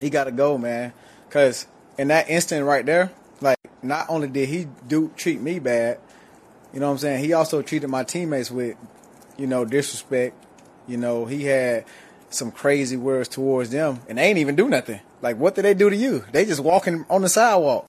Hill expanded on his reaction to the detainment in a local news conference.
Dolphins-Presser-The-Officer-Has-To-Go.mp3